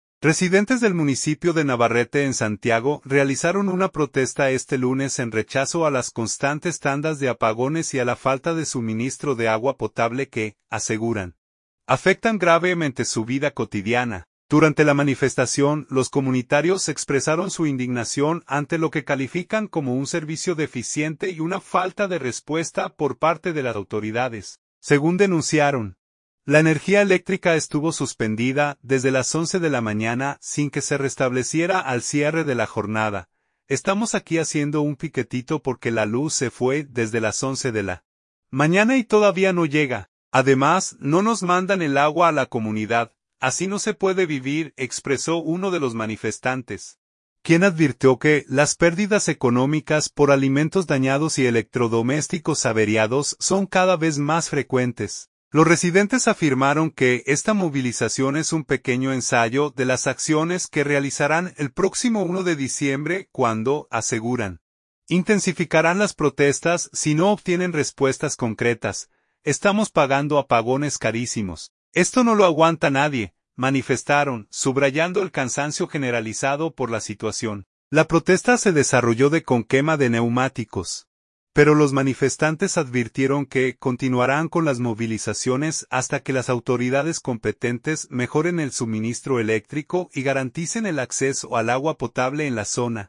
Residentes del municipio de Navarrete en Santiago realizaron una protesta este lunes en rechazo a las constantes tandas de apagones y a la falta de suministro de agua potable que, aseguran, afectan gravemente su vida cotidiana.
“Estamos aquí haciendo un piquetito porque la luz se fue desde las 11 de la mañana y todavía no llega. Además, no nos mandan el agua a la comunidad. Así no se puede vivir”, expresó uno de los manifestantes, quien advirtió que las pérdidas económicas por alimentos dañados y electrodomésticos averiados son cada vez más frecuentes.